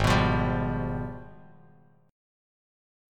AbmM13 chord